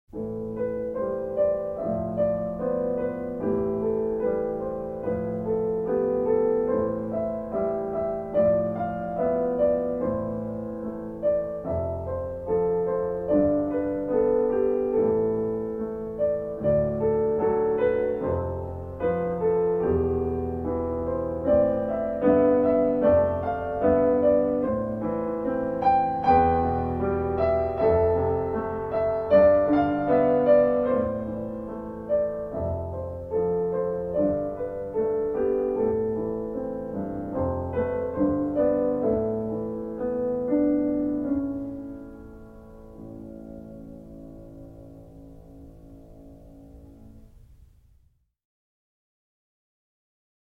Solo